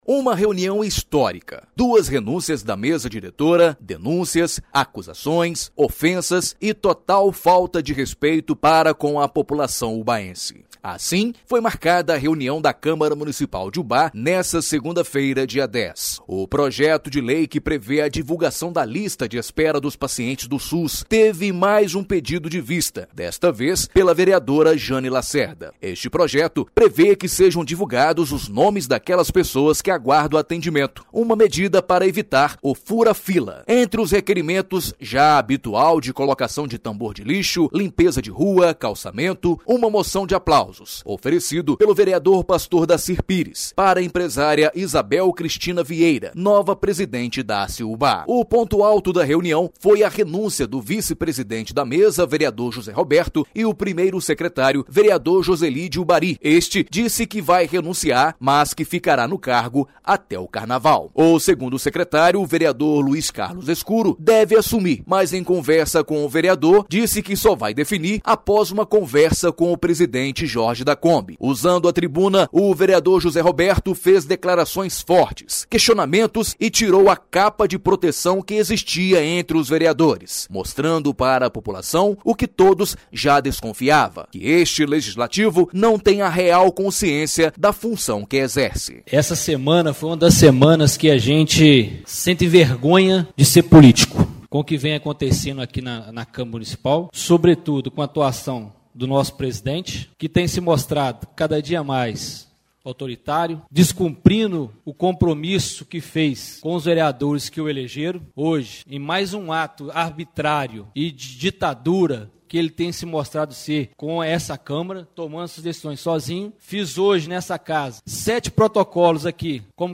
Ouça o resumo da reunião do informativo Câmara – exibido na Rádio Educadora AM/FM.